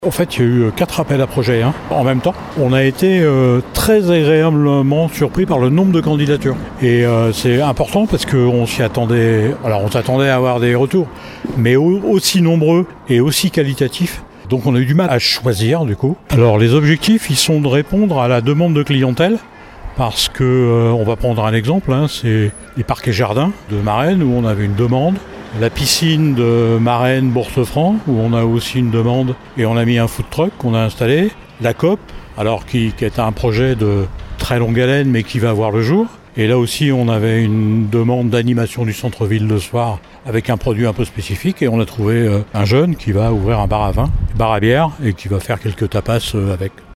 L’adjoint au maire en charge de la vie économique Philippe Lutz s’en réjouit :